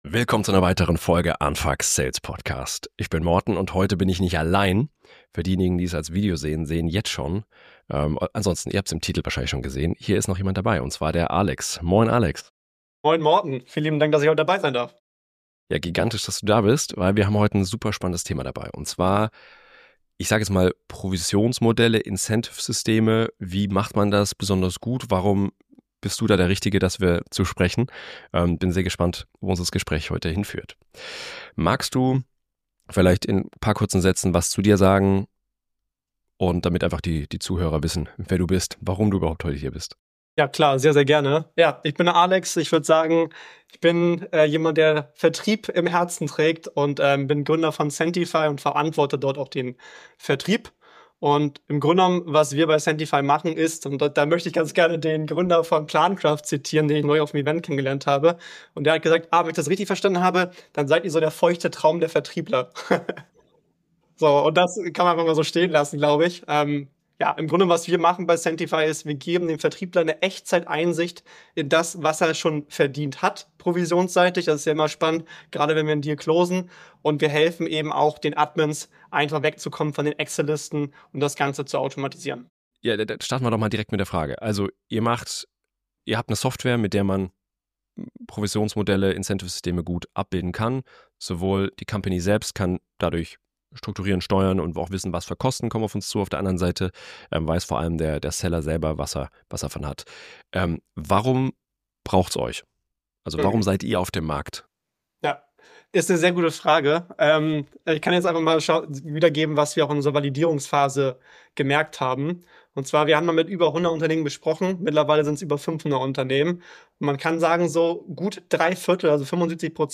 Wie berechne ich meinen Bonus? Interview